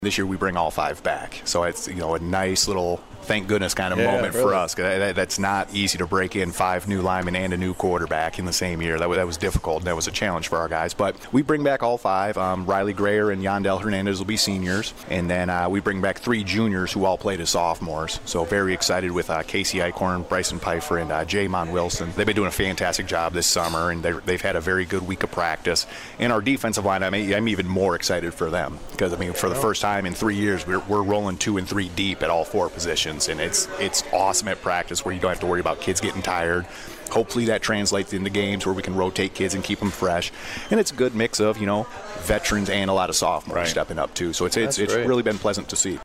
96.5 The Cave and Lenawee TV held their annual Football Coaches Preview Show Sunday at Hometown Pizza at the Lakes…in Manitou Beach.